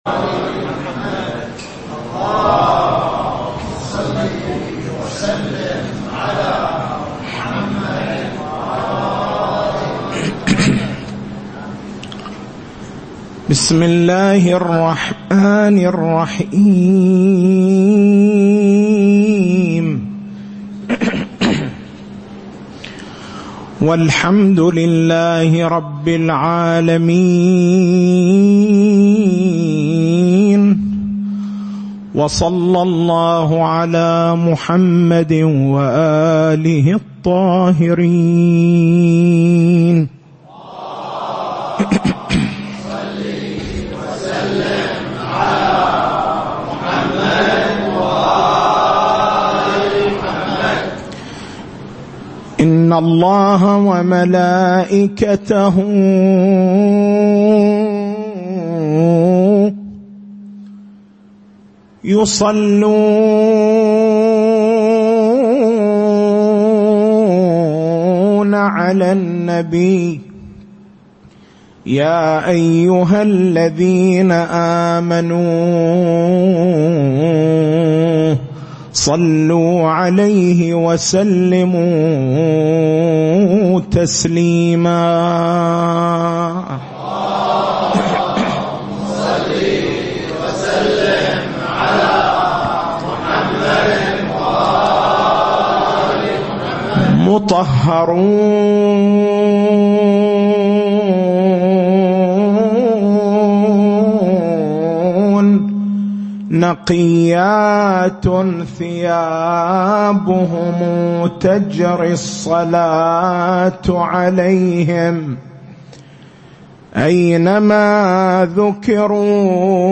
تاريخ المحاضرة: 30/09/1440 محور البحث: استعراض الدليل الثاني من الأدلة الخاصّة لإثبات ولادة الإمام المهدي (عجل الله تعالى فرجه الشريف)، وهو دليل الشهادات.